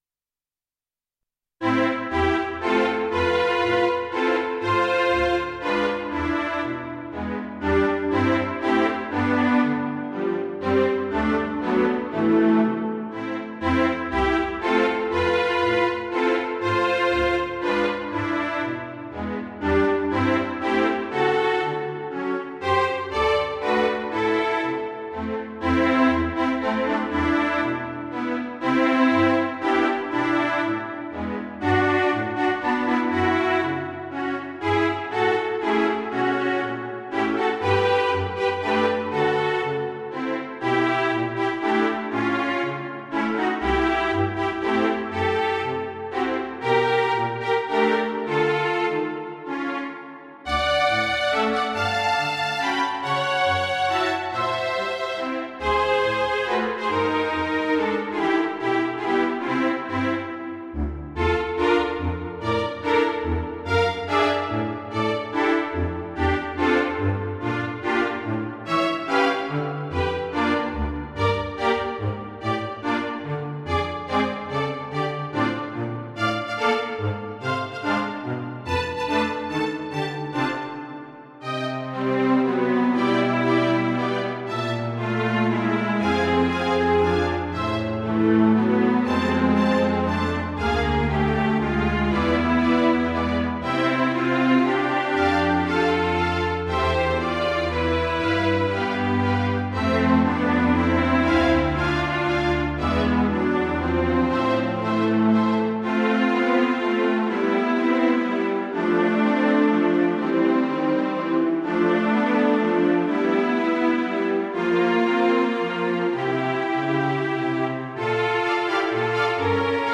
ＭＰ３(2.8MB） 短い旋律を集めたもの。不協和音が顔を出しますが、生き生きとした感じはあると思います。